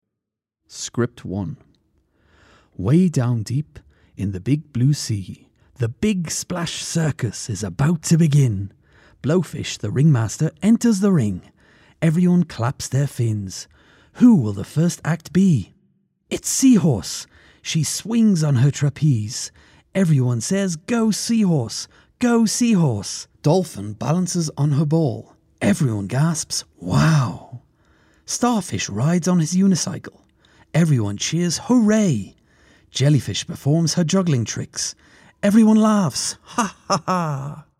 I've a comedic style naturally but I can also do slower, more serious pieces when required.
irisch
Sprechprobe: eLearning (Muttersprache):